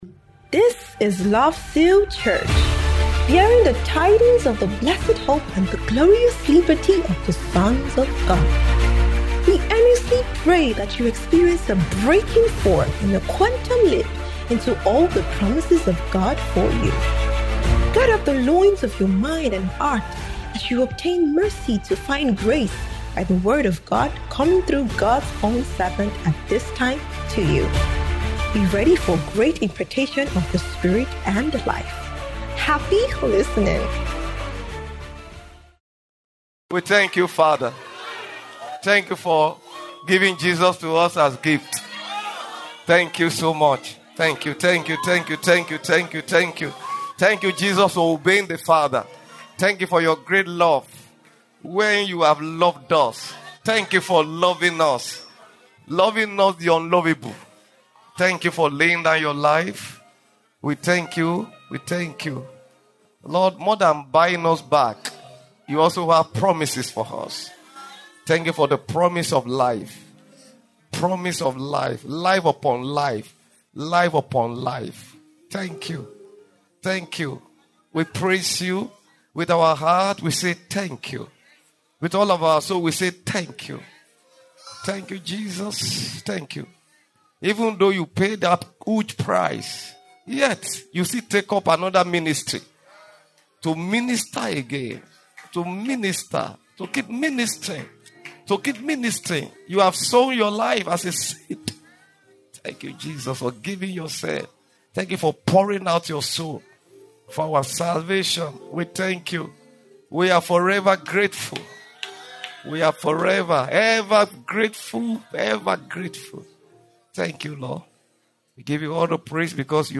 SUNDAY APOSTOLIC BREAKTHROUGH IMPARTATION SERVICE